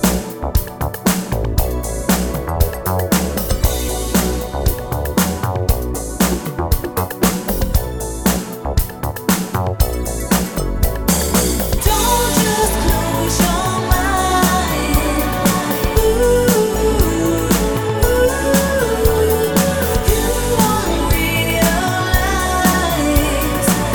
Cut Down Slightly Soul / Motown 3:30 Buy £1.50